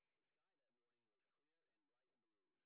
sp10_train_snr30.wav